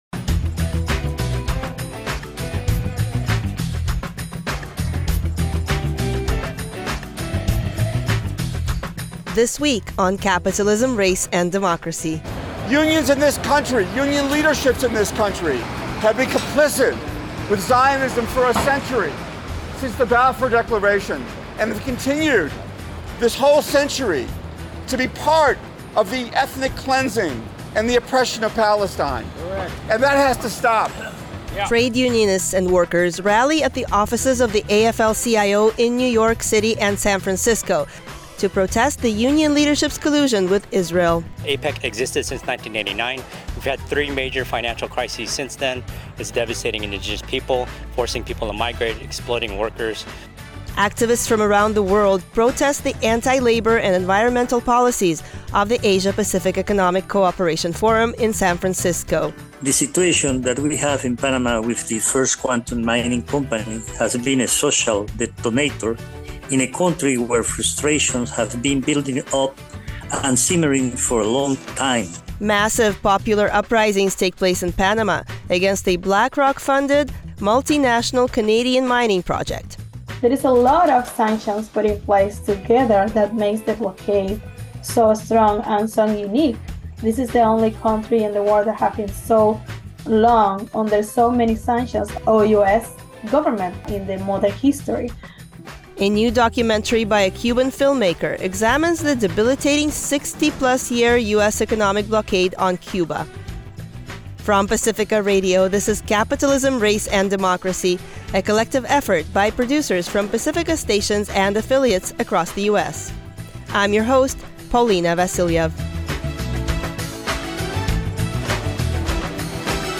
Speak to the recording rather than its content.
The No to APEC Coalition, representing over 100 grassroots organizations, held a People’s Summit at San Francisco State University on Saturday.